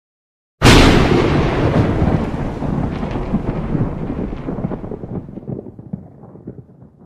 Big Thunder Sound Button - Free Download & Play